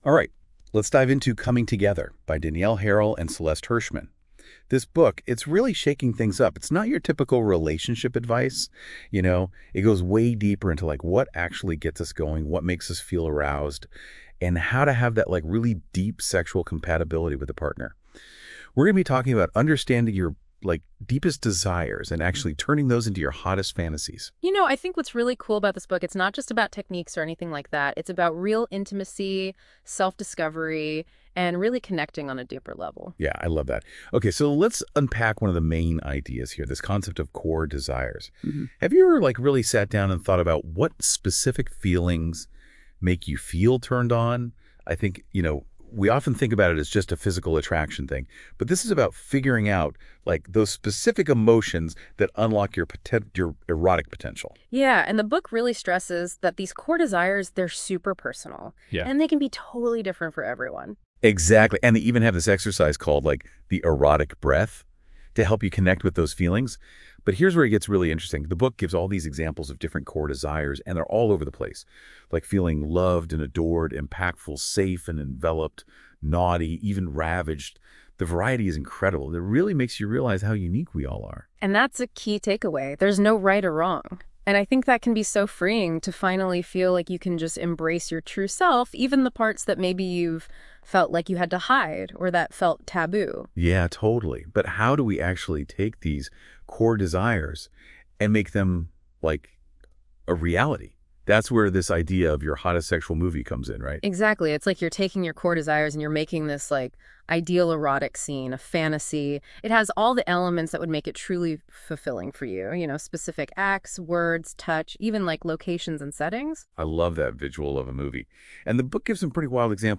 Coming-Together-AI-podcast.mp3